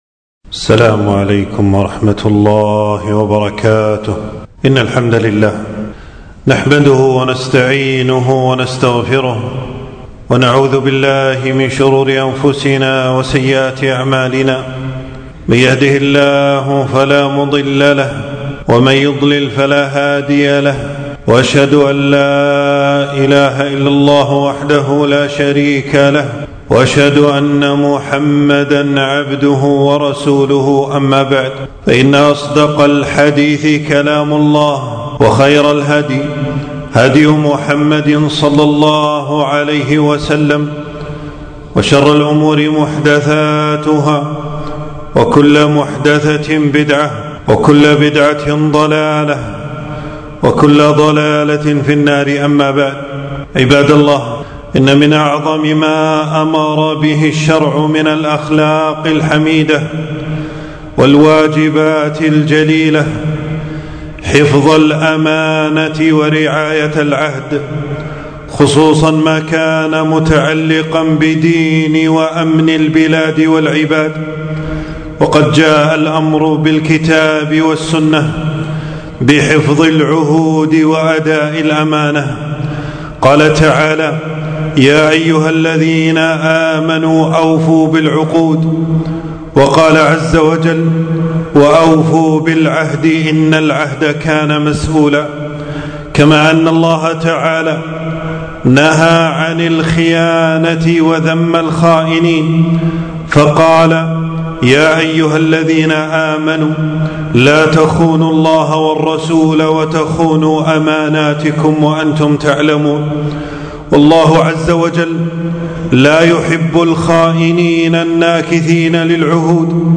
تنزيل تنزيل التفريغ خطبة بعنوان: خيانة الأوطان .
المكان: في مسجد - أبو سلمة بن عبدالرحمن 15 شوال 1447هـ (بمدينة المطلاع).